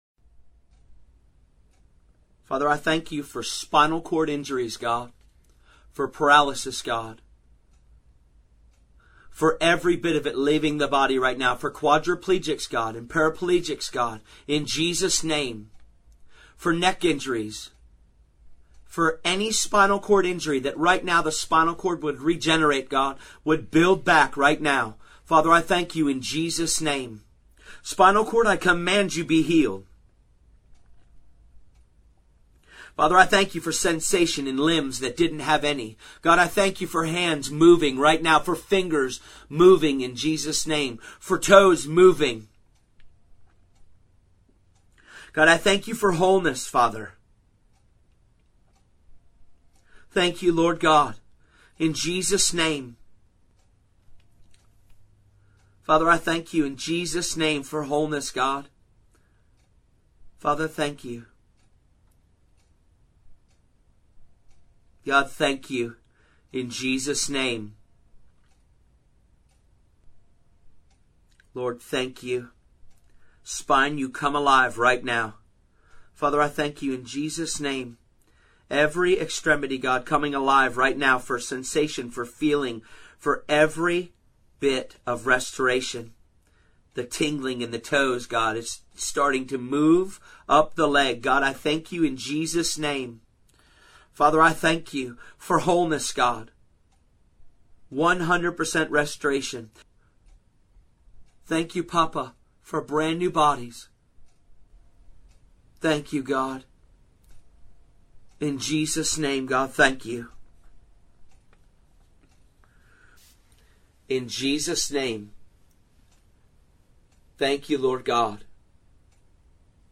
Prayer
Spinal Cord Injury Prayer.mp3